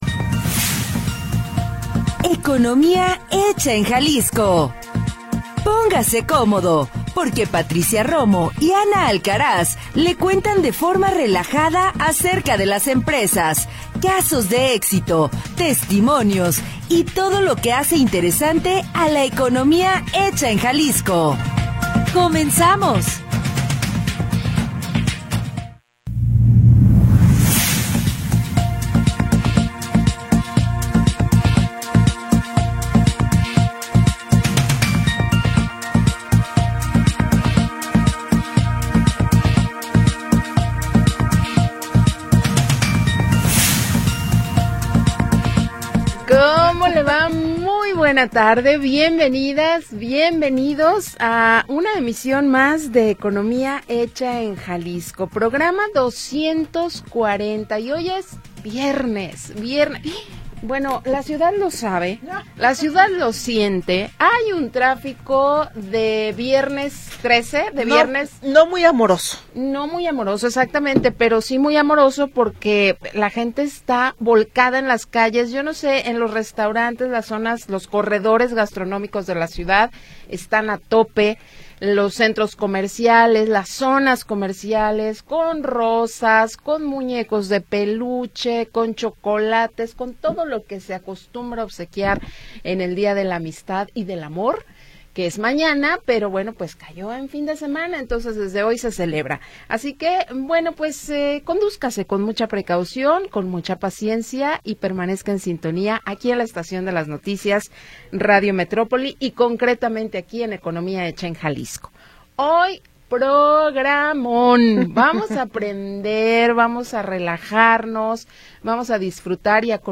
le cuentan de forma relajada acerca de las empresas, casos de éxito, testimonios y todo lo que hace interesante a la economía hecha en Jalisco. Programa transmitido el 13 de Febrero de 2026.